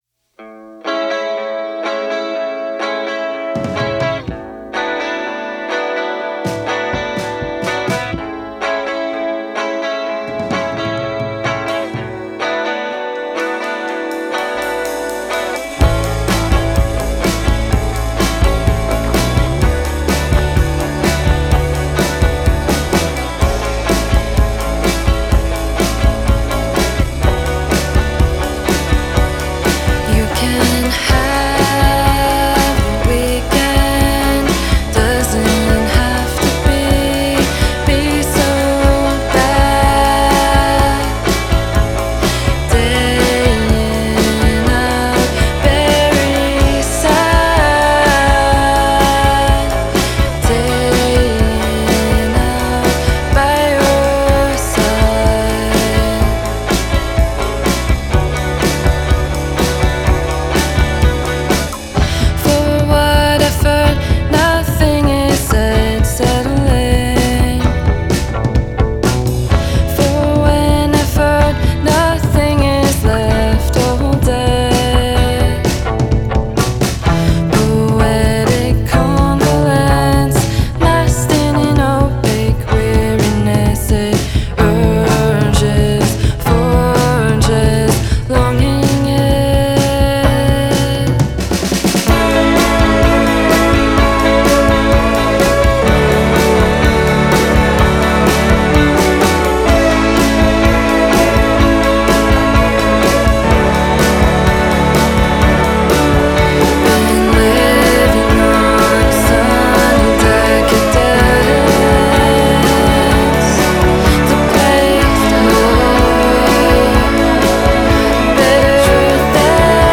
Characterized as Dreampop